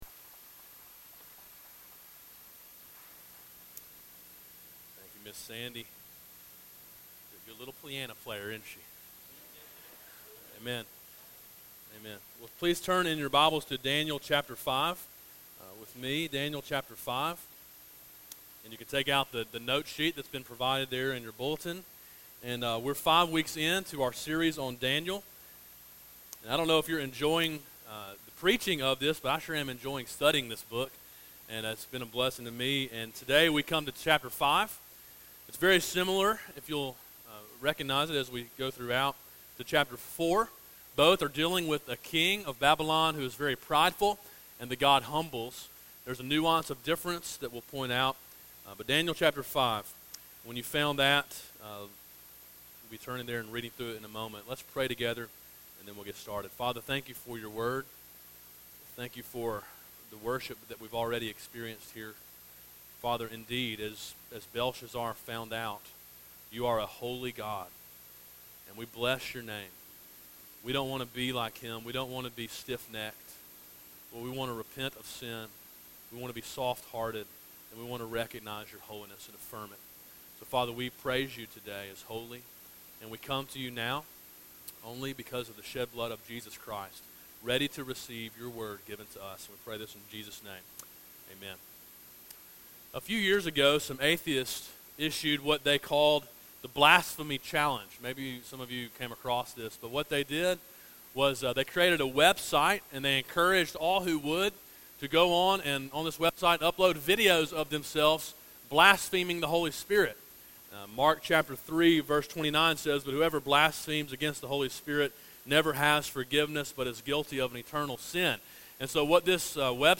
A sermon in a series on the book of Daniel.